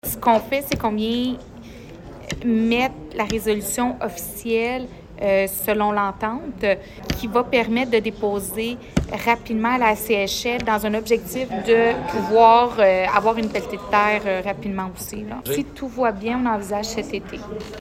Nouvelles
Julie Bourdon, mairesse de Granby